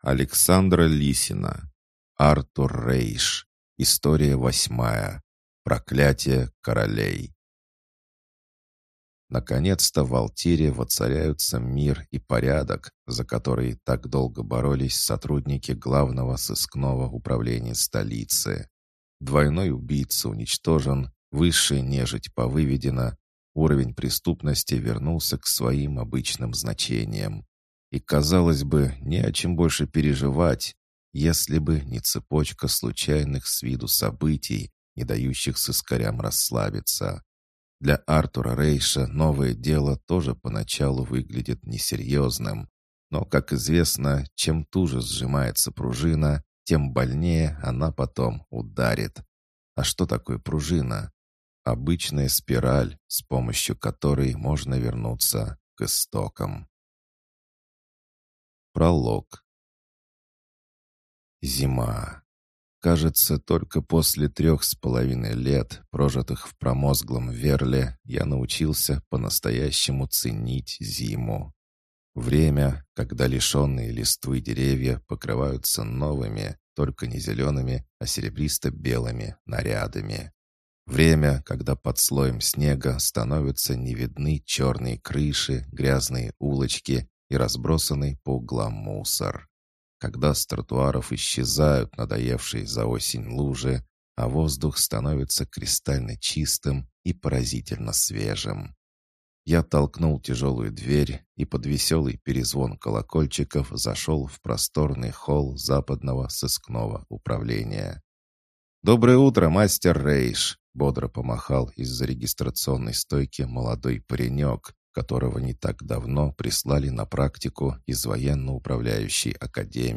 Аудиокнига Проклятие королей | Библиотека аудиокниг